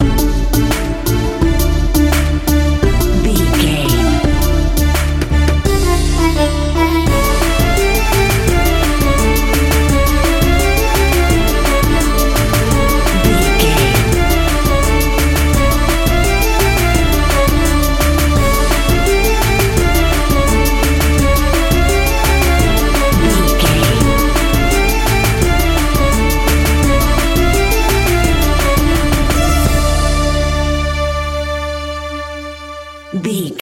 Aeolian/Minor
DOES THIS CLIP CONTAINS LYRICS OR HUMAN VOICE?
Slow
World Music
percussion